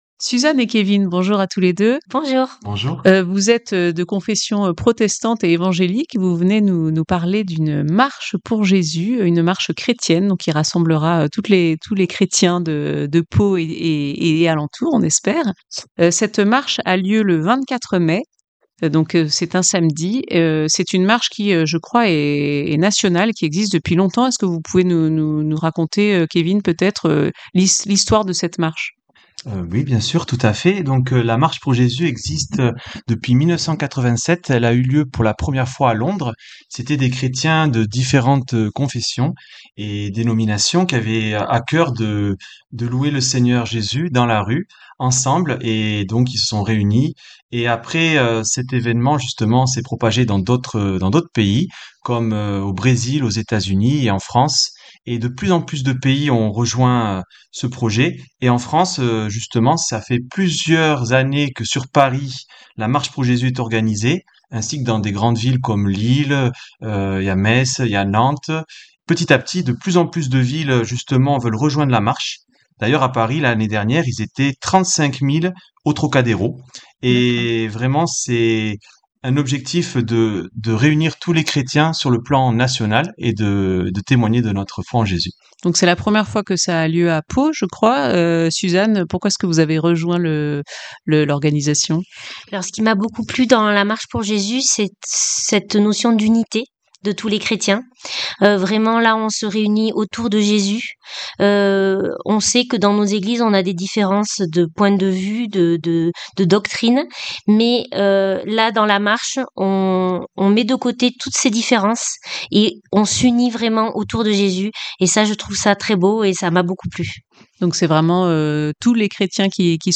Deux membres d’Eglises protestantes et évangéliques nous présentent cette marche qui rassemblera tous les chrétiens samedi 24 mai à Pau et dans de nombreuses villes en France.